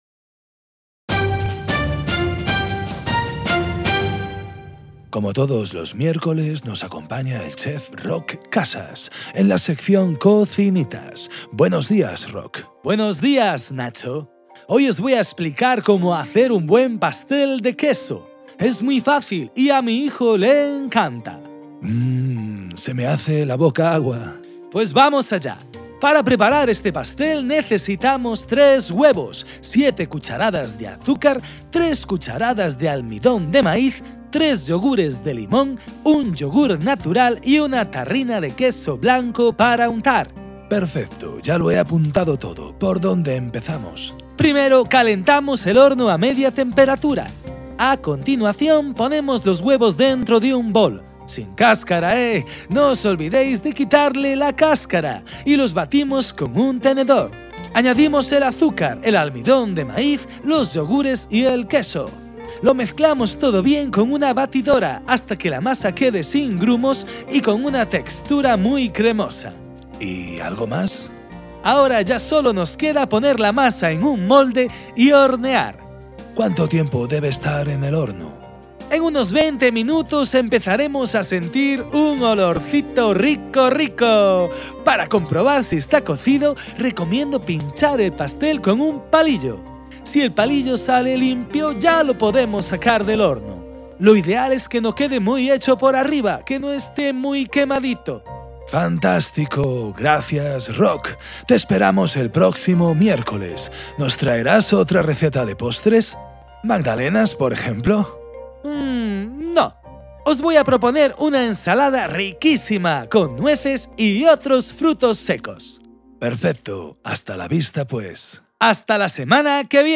AUDIORECETA
Entrevista